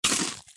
crunchy-bite-95979.mp3